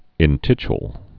(ĭn-tĭchl)